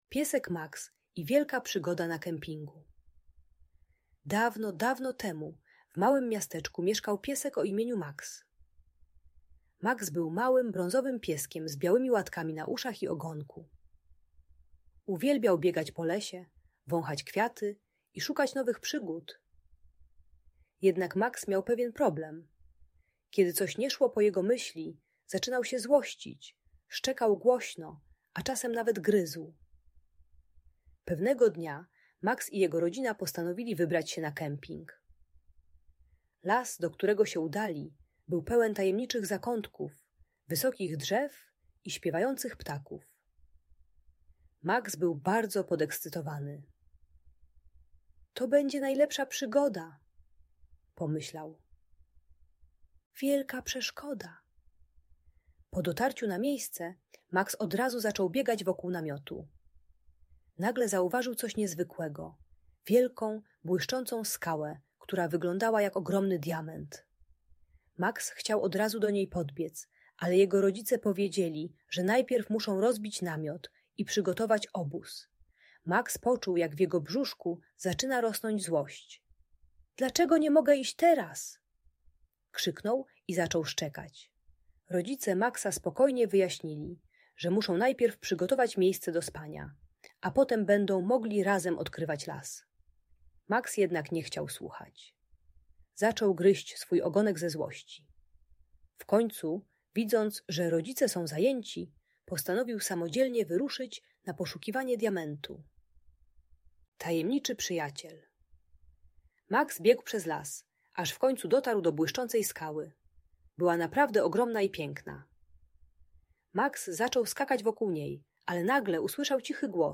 Piesek Max i Wielka Przygoda - Agresja do rodziców | Audiobajka